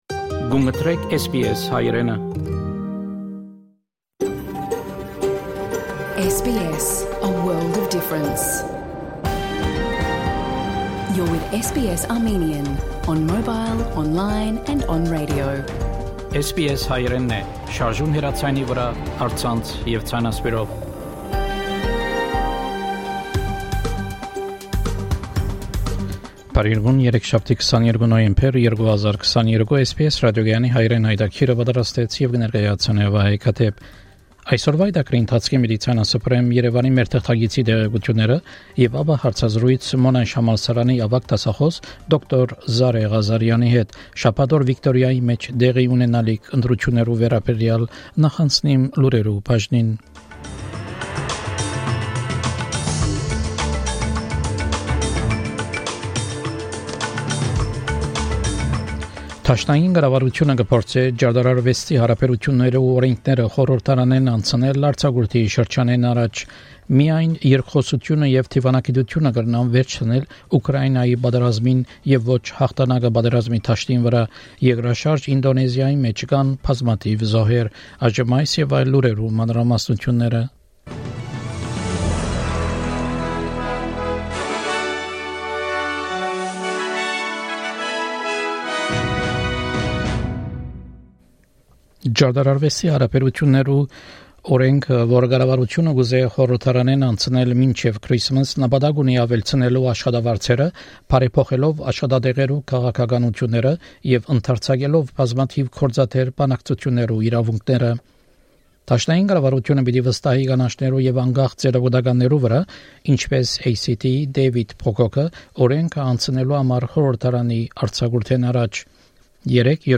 SBS Armenian news bulletin – 22 November 2022